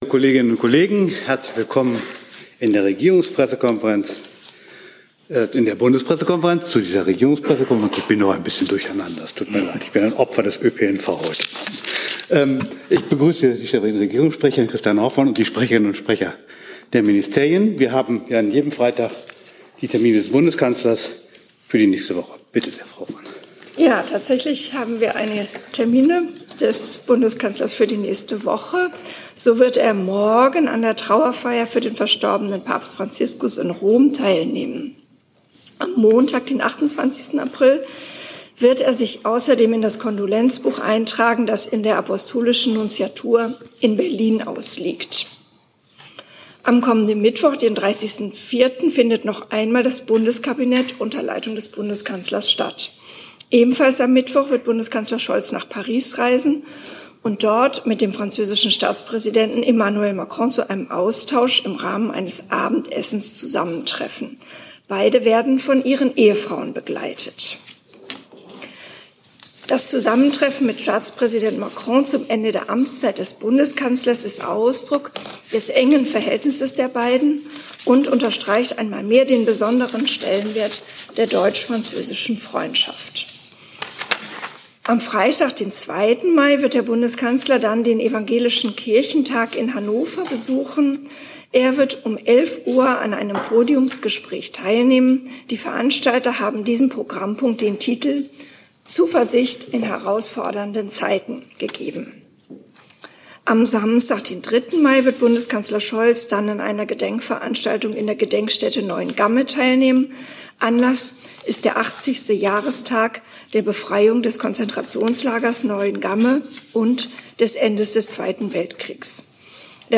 Komplette Regierungspressekonferenzen (RegPK) und andere Pressekonferenzen (BPK) aus dem Saal der Bundespressekonferenz.